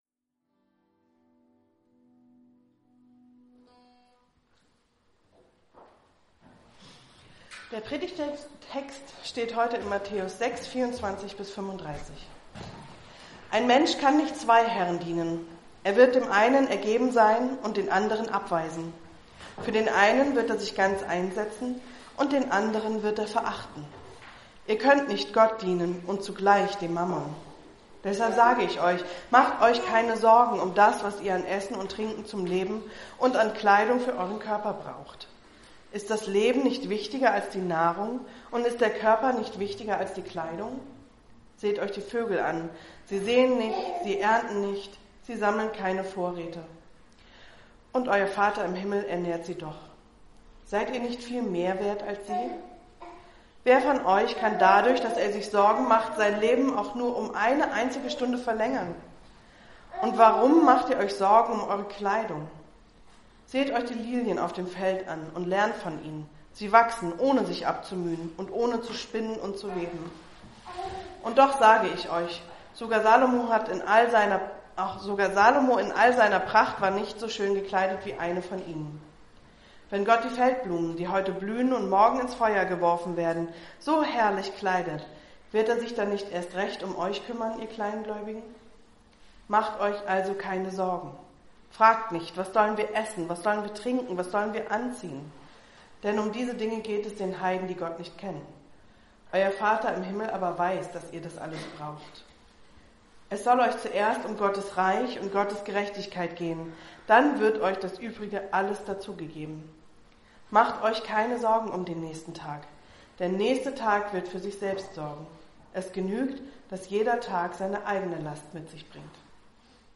Eine predigt aus der serie "GreifBar+." Die Werte von GreifBar